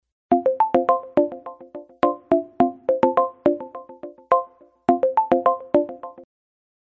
Вы можете слушать онлайн и скачать бесплатно в mp3 рингтоны входящих звонков, мелодии смс-уведомлений, системные звуки и другие аудиофайлы.